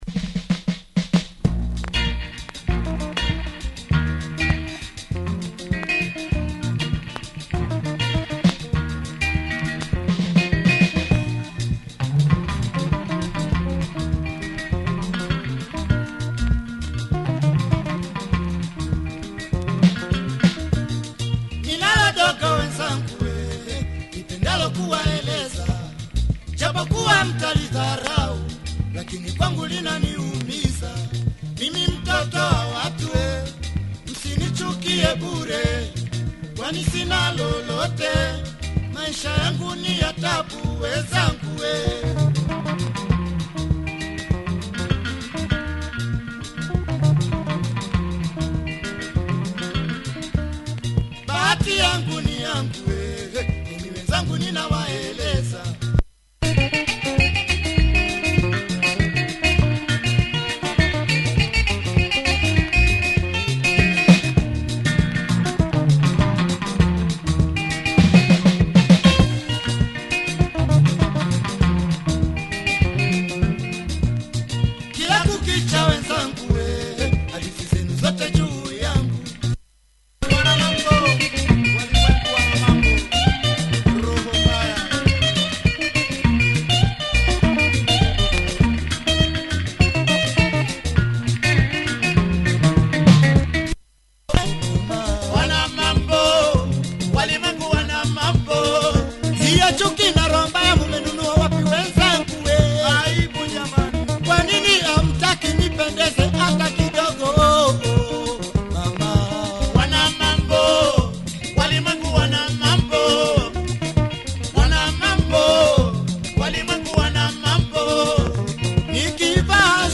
Good track from this Tanzanian band https